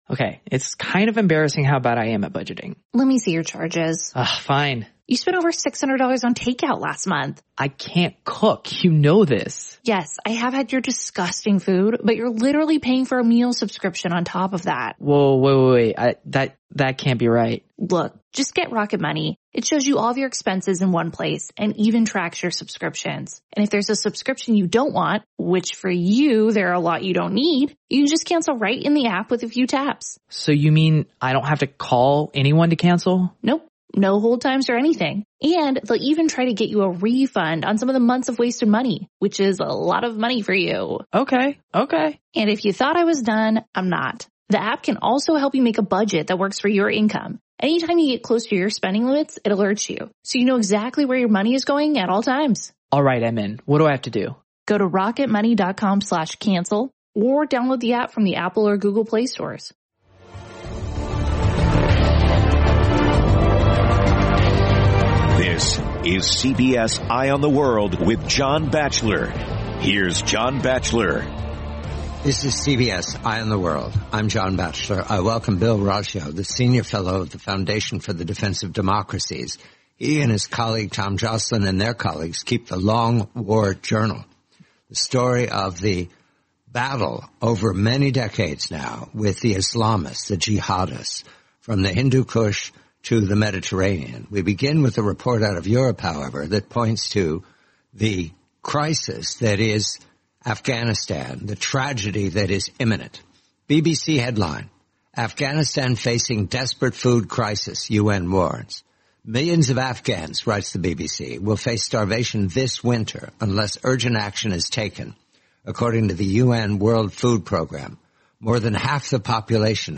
the complete, forty-minute interview